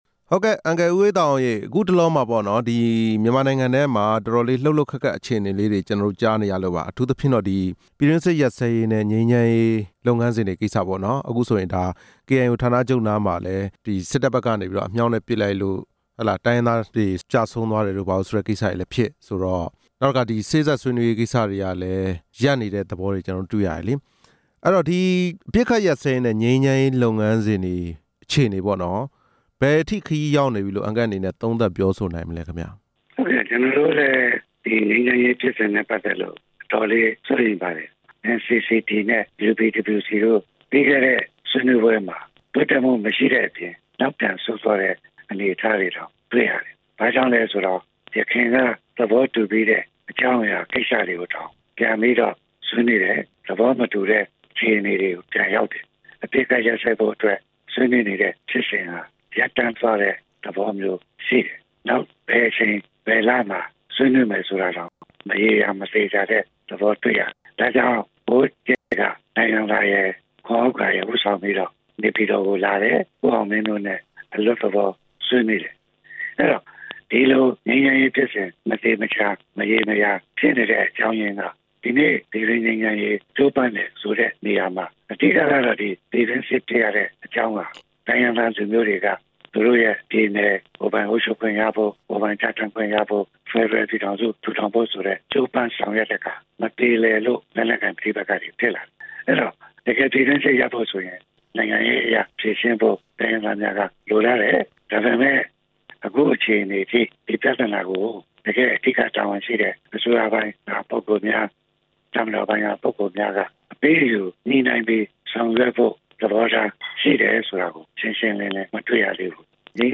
မေးမြန်းချက် အပြည့်အစုံ